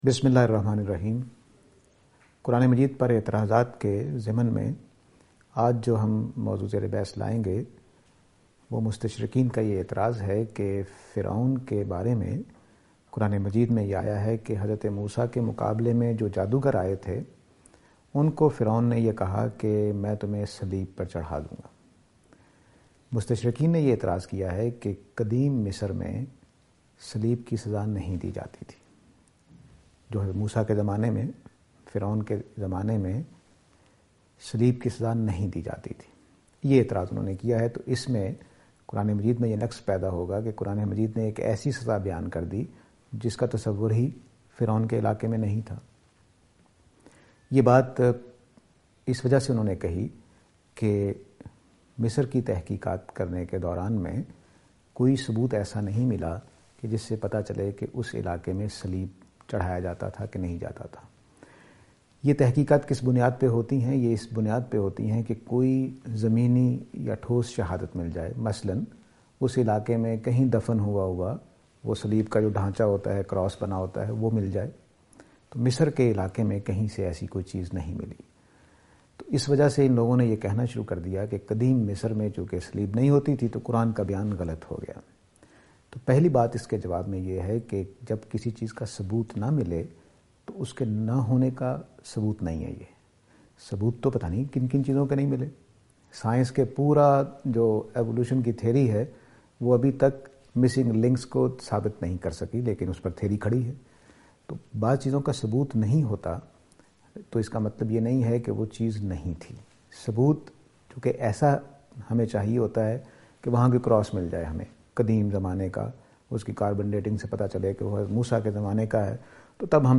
This lecture will present and answer to the allegation "Punishment of cross in old Egypt".